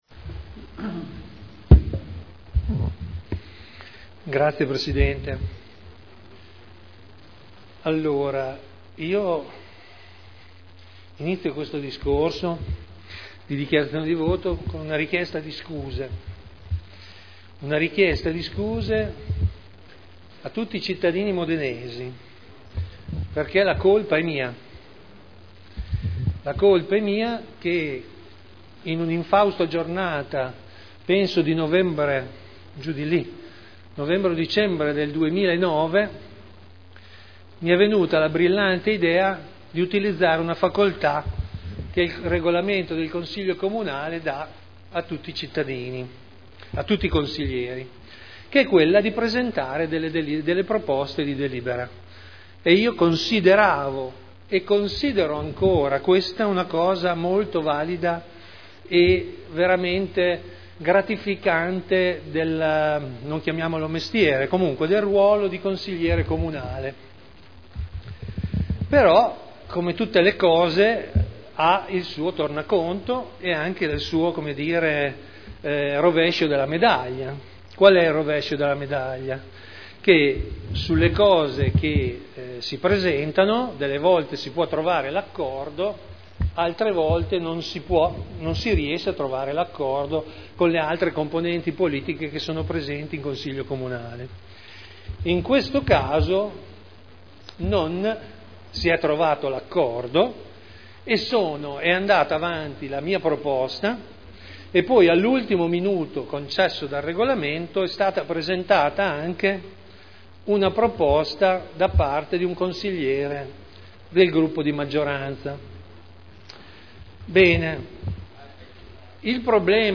Seduta del 18 ottobre 2010 - Dichiarazione di voto su: Modifica dell’art. 3 – comma 5 dello Statuto comunale (Proposta di deliberazione dei consiglieri Trande e Campioli) (Commissione consiliare del 9 luglio 2010 – parere positivo)